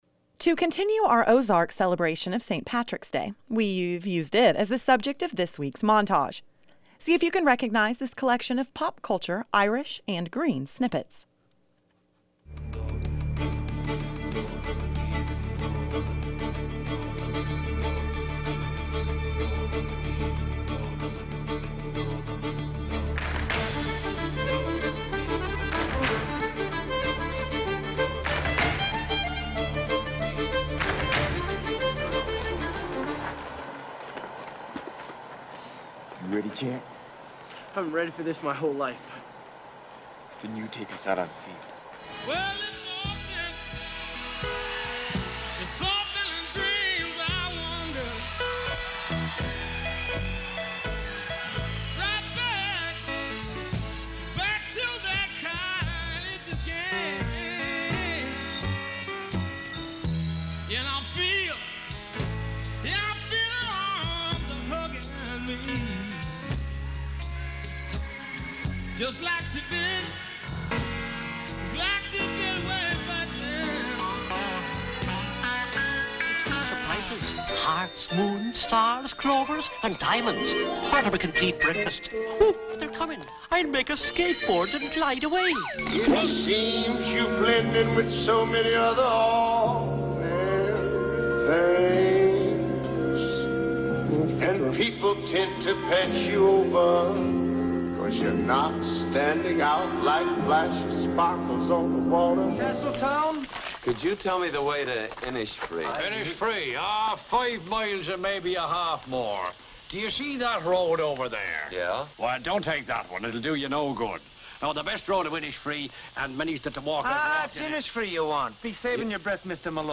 Here are the 9 pop culture references used for today's montage: "Reel Around the Sun" from a 1995 performance of Riverdance Rudy gets ready to lead the Fighting Irish onto the field for the first, and only, time The Band backing Van Morrison on "Tura Lura Lura (It's an Irish Lullaby)" on Thanksgiving night, 1976 A Lucky Charms commercial from the early '70s Kermit the Frog being upstaged by none other than the late, great Ray Charles singing "It's Not Easy Being Green" John Wayne tries to get his bearings from local Dubliners upon his arrival in Ireland in The Quiet Man A 1979 commercial for Irish Spring soap John Lennon singing "The Luck of the Irish" A recent commercial for Jameson Irish Whiskey